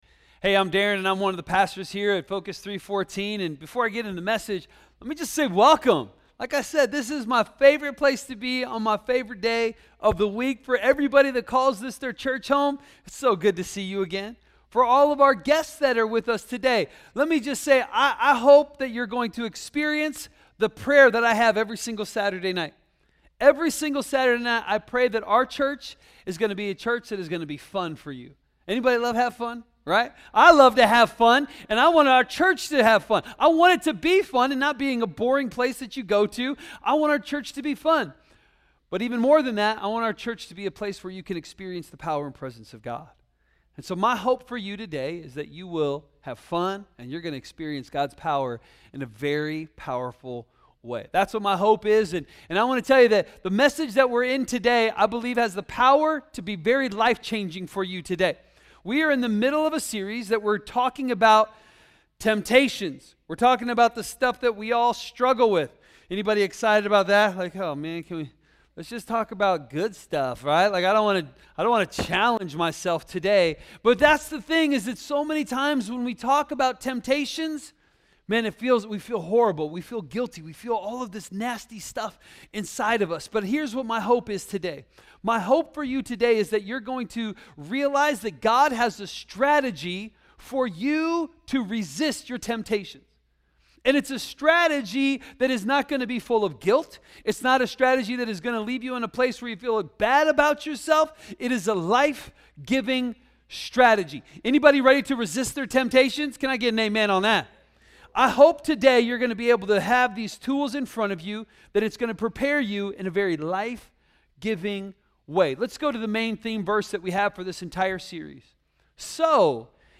This is week 3 of a sermon on how to face temptation.